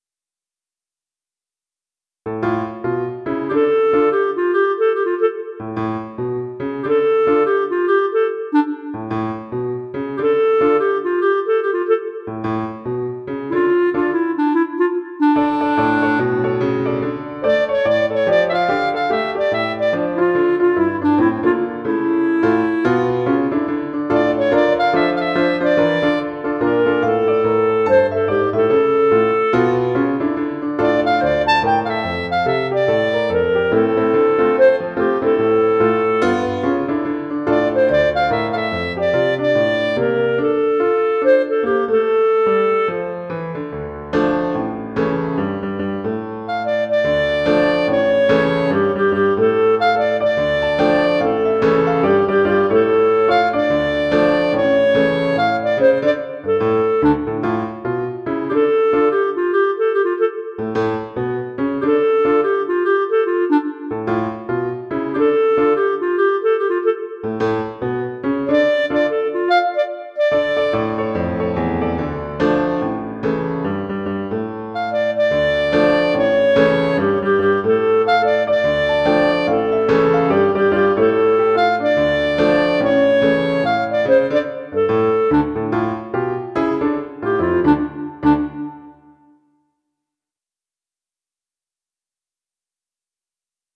Title Little Bop V Opus # 262 Year 2006 Duration 00:01:34 Self-Rating 4 Description Sorry about the constant bridge jumps. If you transpose to a key you like better, go for it. mp3 download wav download Files: mp3 wav Tags: Duet, Piano, Clarinet Plays: 1547 Likes: 0